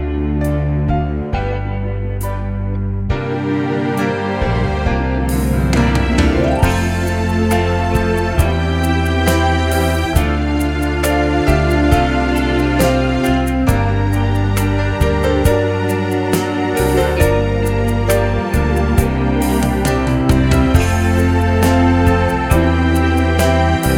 With Oboe Solo Crooners 4:21 Buy £1.50